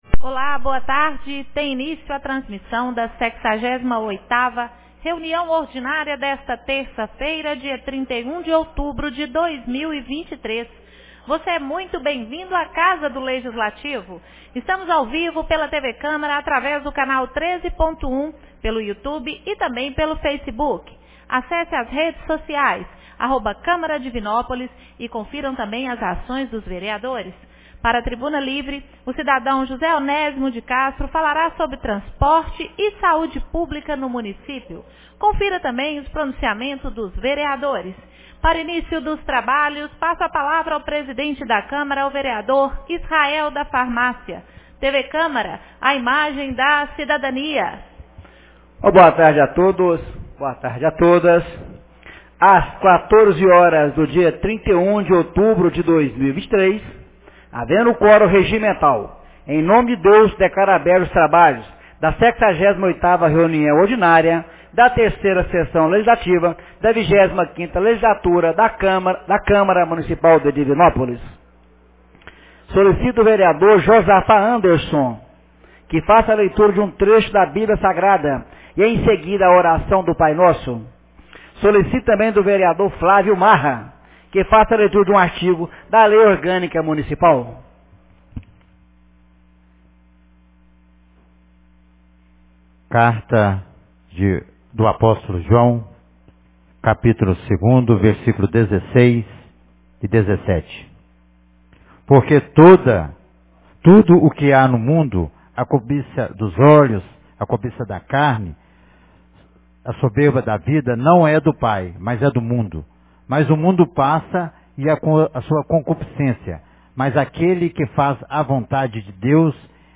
68ª Reunião Ordinária 31 de outubro de 2023